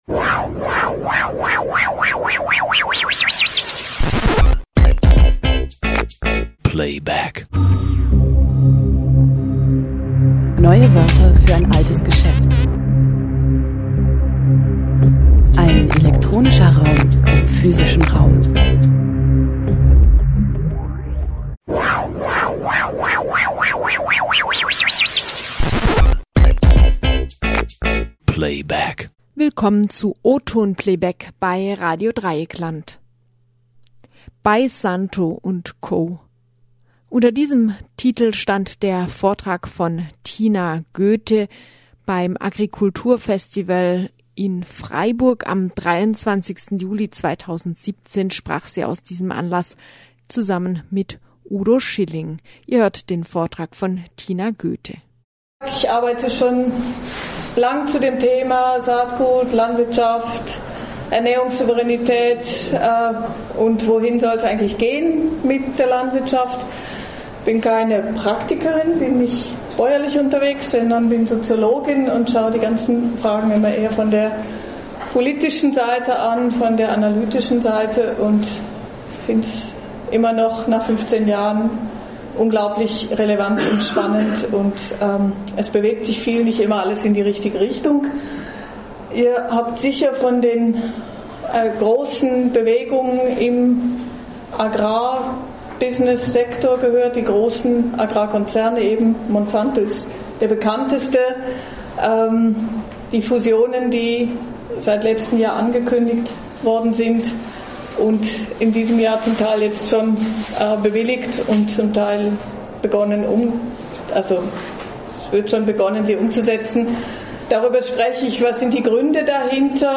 Sendezeit: Jeden Freitag im Monat um 19 Uhr (Wiederholung: Dienstag um 14 Uhr)In O-Ton Playback kommen Veranstaltungen und Lesungen nahezu unverkürzt zu Gehör.